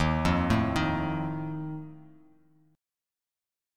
Ebsus2b5 chord